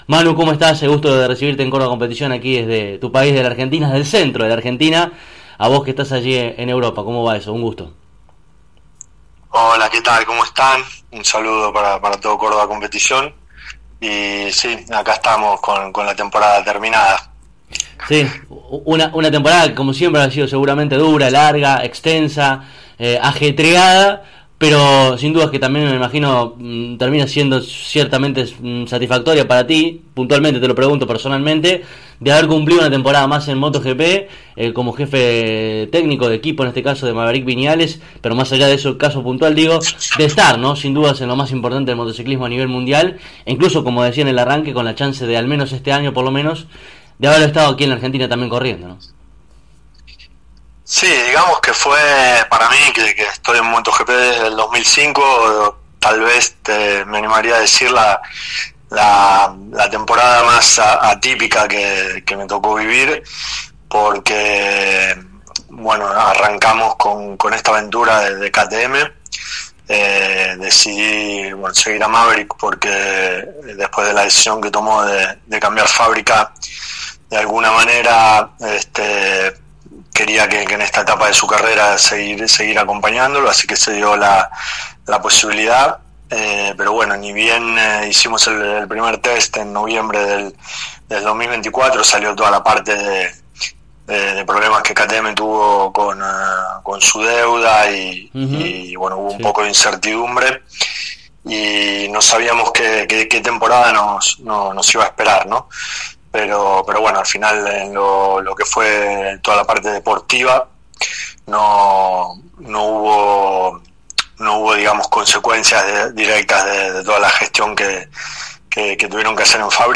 A continuación podrás escuchar esta interesante entrevista de manera completa: